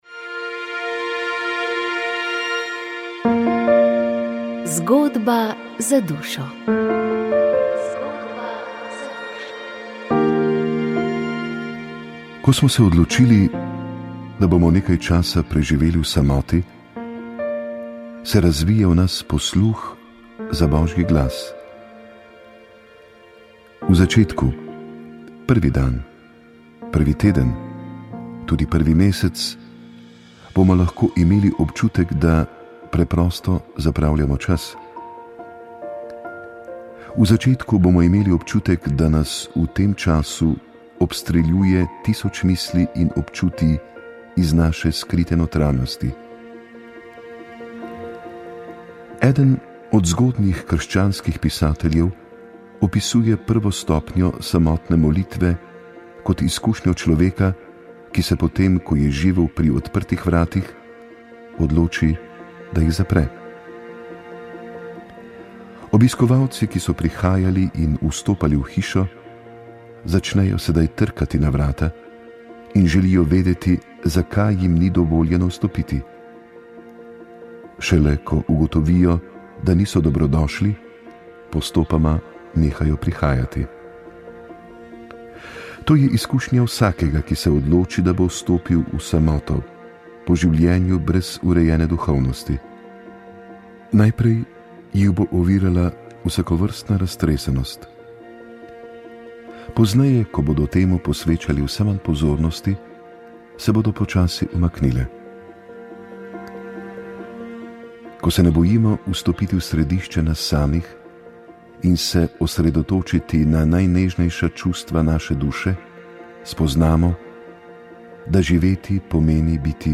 V ta namen je prejšnji teden potekala okrogla miza o tej tako zelo občutljivi temi življenja.